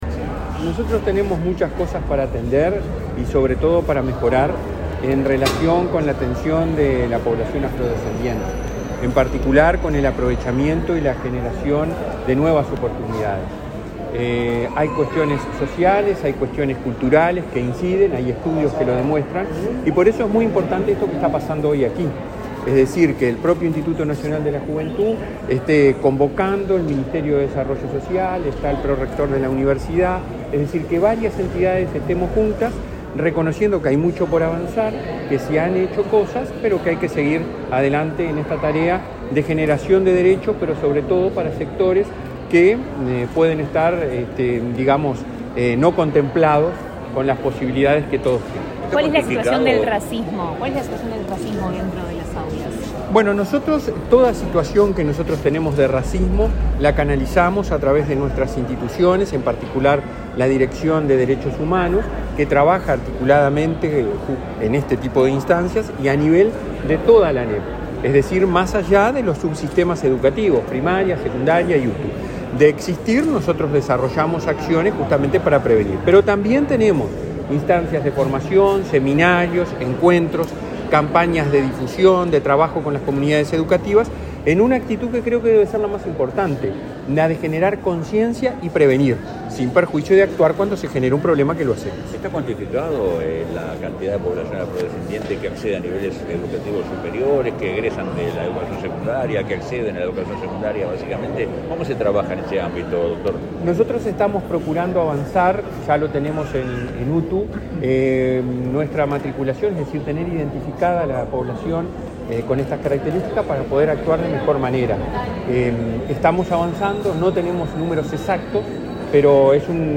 Declaraciones del presidente del Codicen, Robert Silva